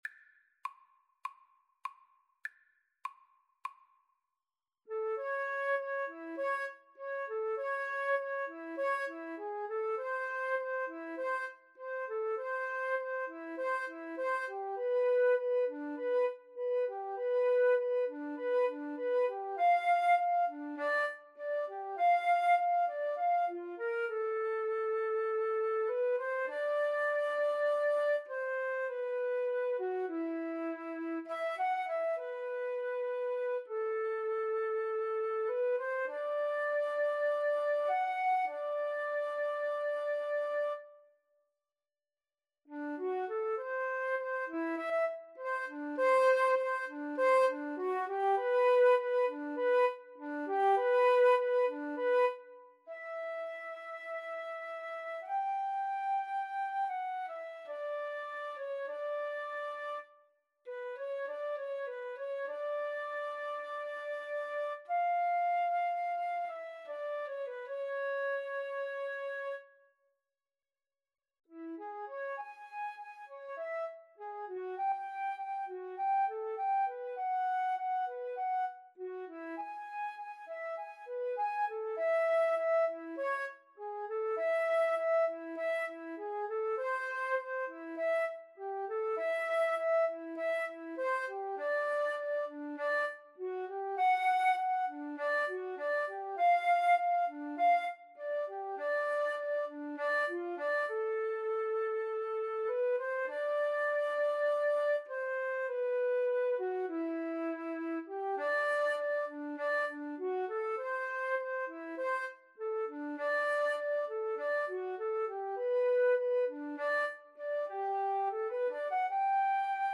A sultry piece in the rhythm known as 'Beguine'.
4/4 (View more 4/4 Music)